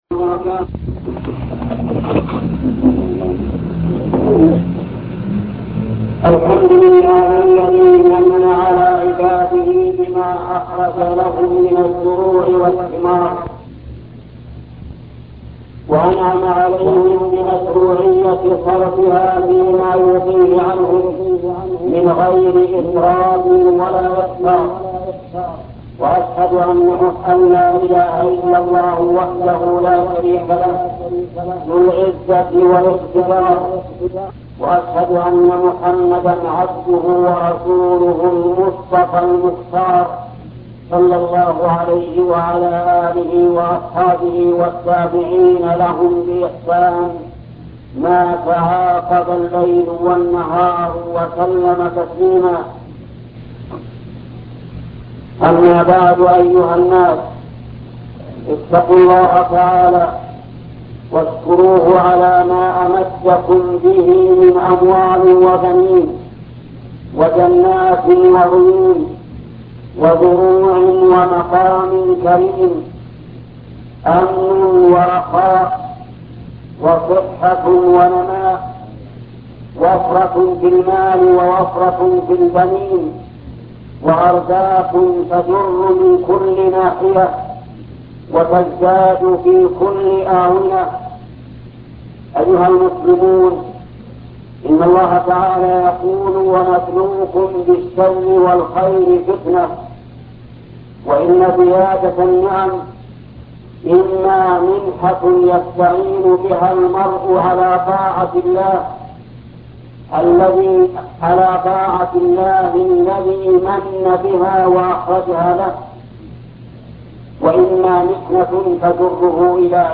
خطبة وجوب إخراج الزكاة - وجوب إخراج زكاة النخيل والنفع الذي يعود على من أخرجها في الدنيا والآخرة الشيخ محمد بن صالح العثيمين